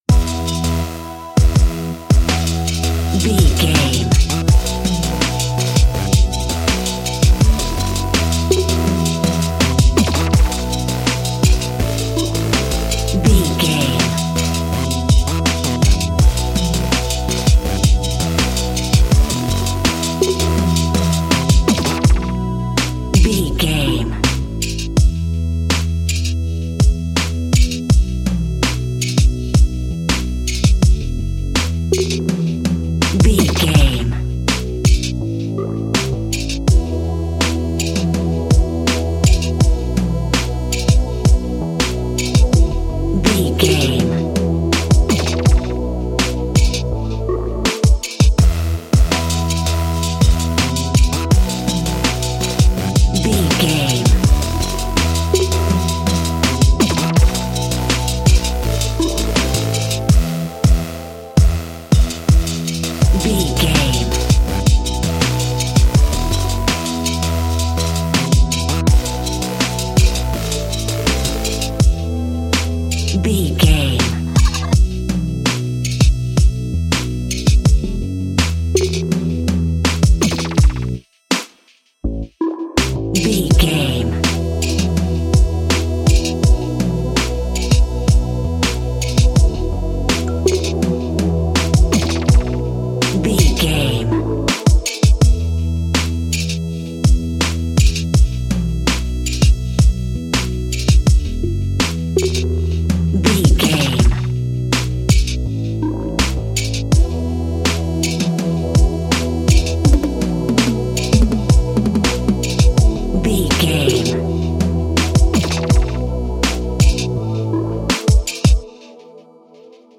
Epic / Action
Fast paced
In-crescendo
Ionian/Major
dreamy
suspense
moody